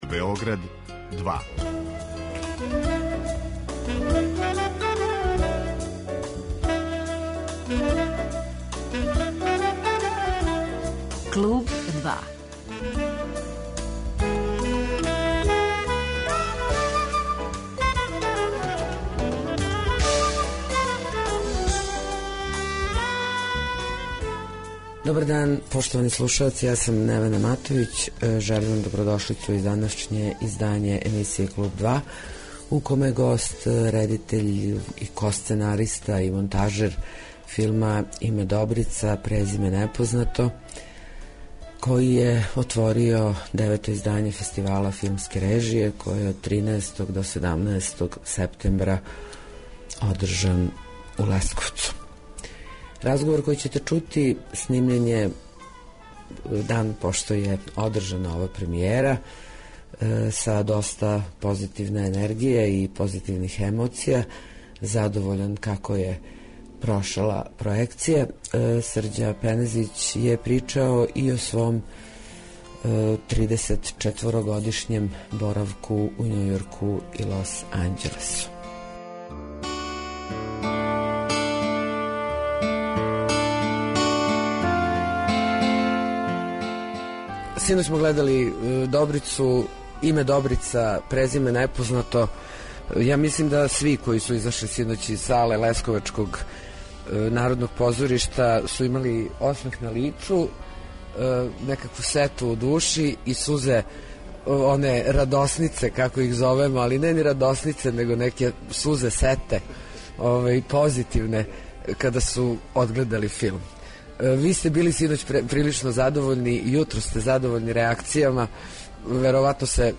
Разговор који ћете чути снимљен је у Лесковцу, дан после премијере филма